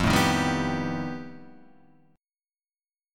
F9b5 chord